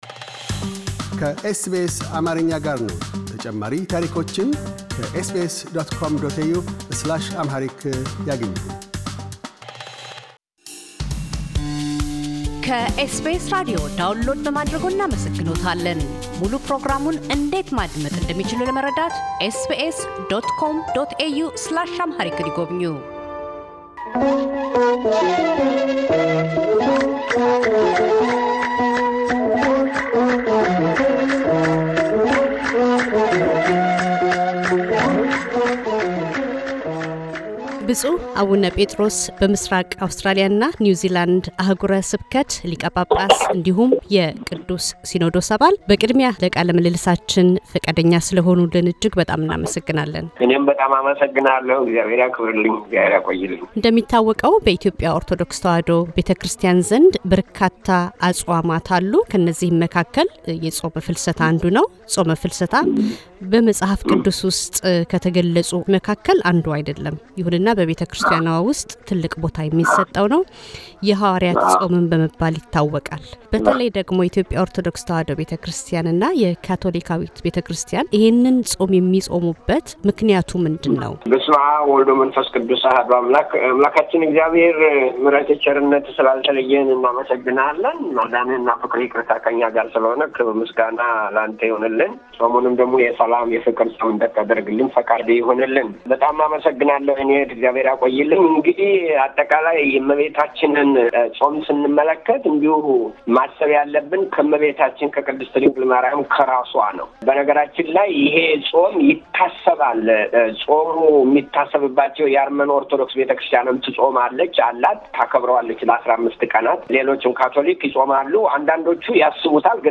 ብፁዕ አቡነ ጰጥሮስ - በኢትዮጵያ ኦርቶዶክስ ተዋሕዶ ቤተክርስቲያን የምሥራቅ አውስትራሊያና ኒውዝላንድ አሕጉረ ስብከት ሊቀ ጳጳስና የቅዱስ ሲኖዶስ አባል፤ የደብረ ታቦርን ሃይማኖታዊና ባሕላዊ አከባበር አስመልክተው ይናገራሉ።